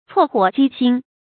cuò huǒ jī xīn
厝火积薪发音
成语正音 积，不能读作“jí”。